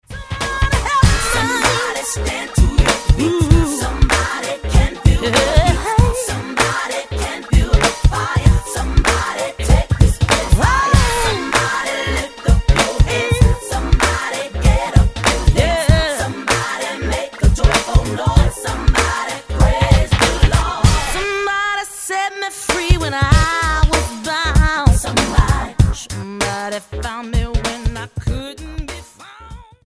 STYLE: Gospel
featuring some impressive vocal acrobatics
a full-on R&B groove